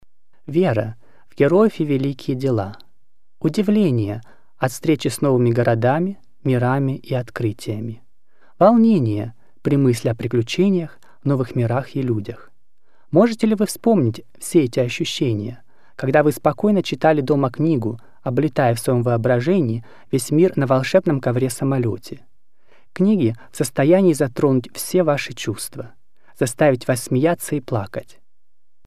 Narration Showreel
Male
Bright
Down To Earth
Friendly